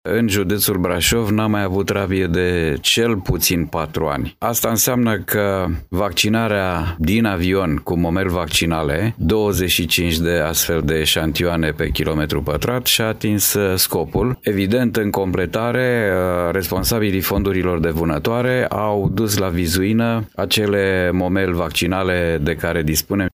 În județul Brașov, de patru ani încoace, nu s-a mai înregistrat niciun caz de rabie, susține directorul Direcției Sanitar-Veterinare, Dorin Enache.